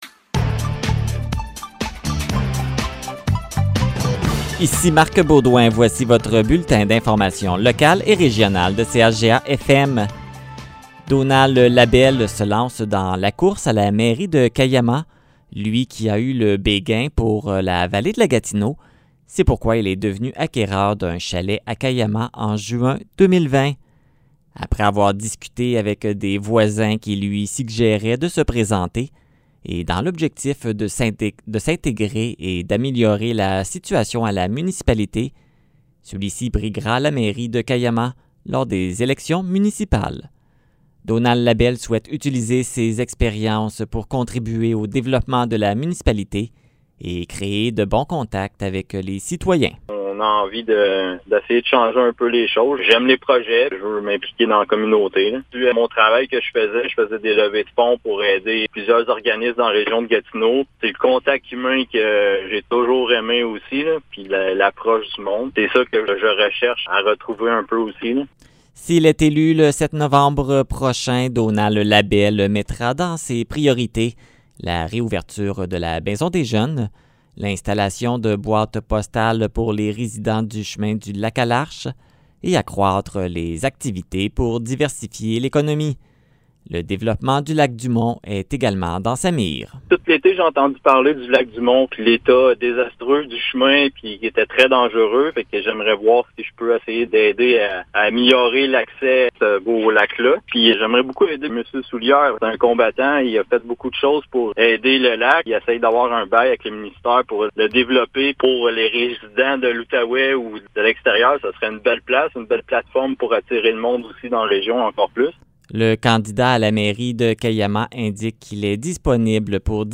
Nouvelles locales - 5 octobre 2021 - 15 h